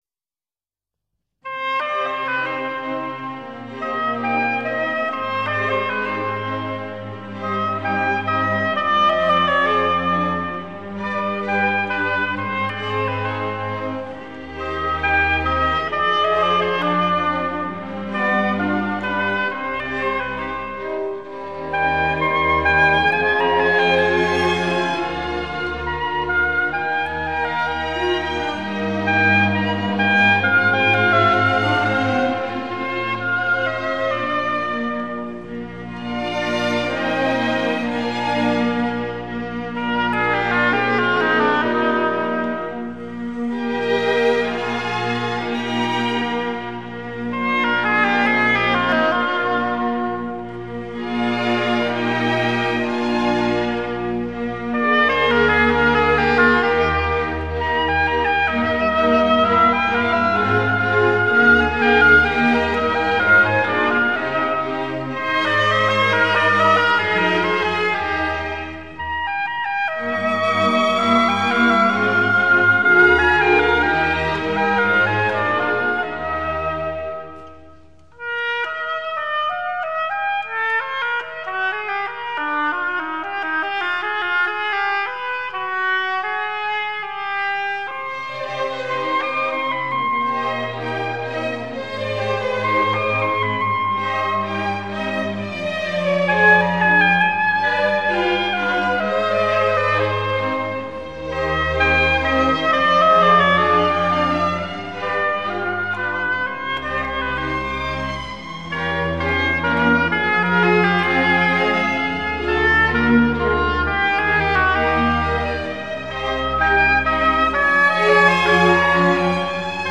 Concerto pour hautbois - 1er mvt : Introduction